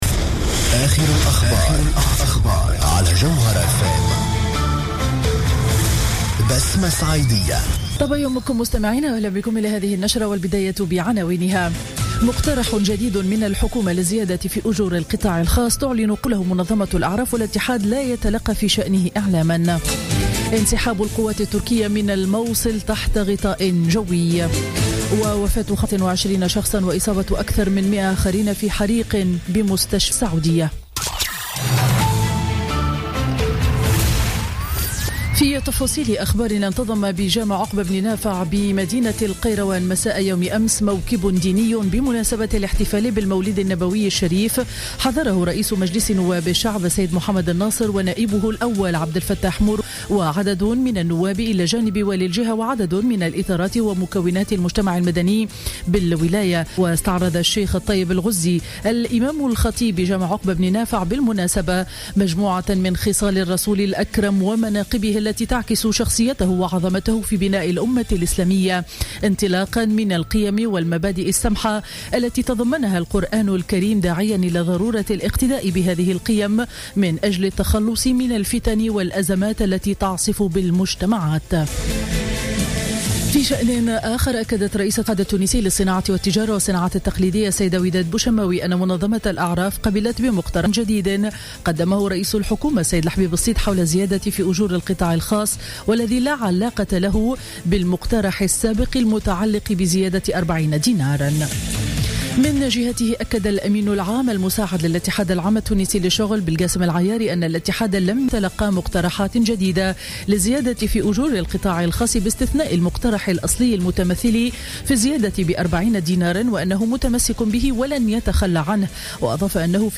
نشرة أخبار السابعة صباحا ليوم الخميس 24 ديسمبر 2015